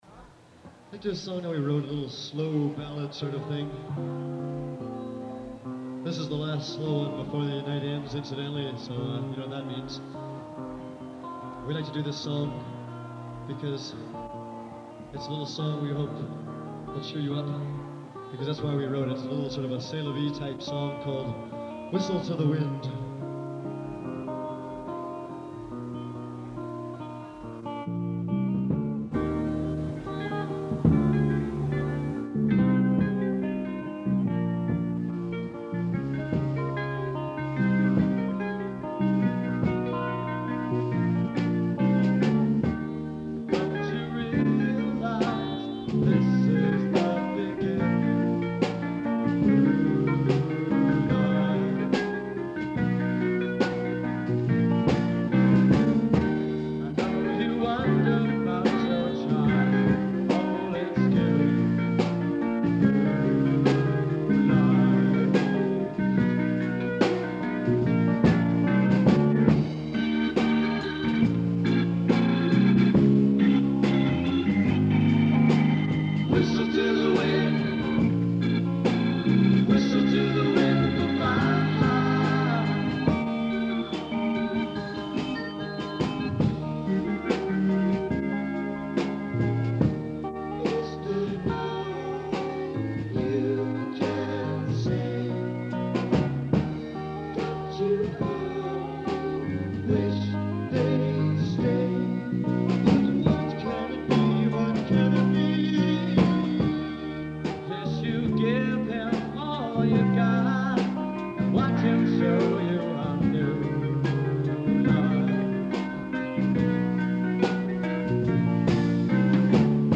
Vox Supercontinental organ